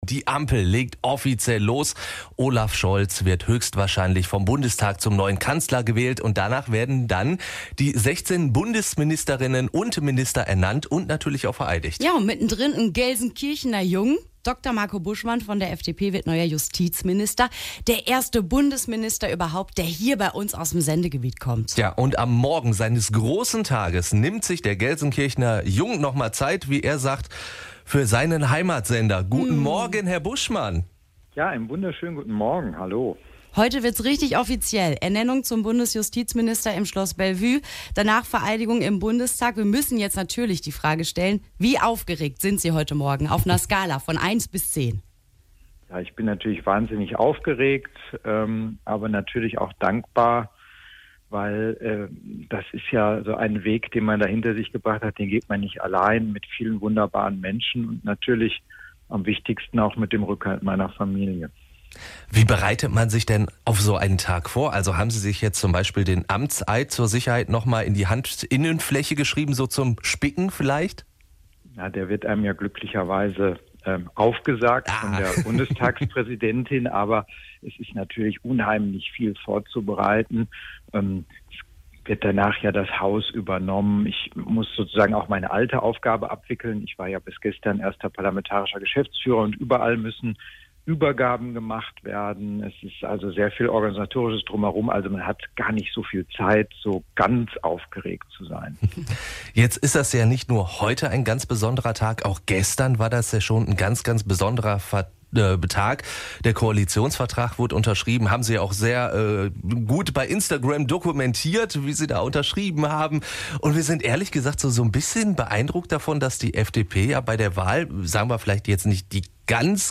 interview-buschmann-komplett.mp3